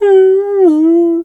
bear_pain_whimper_01.wav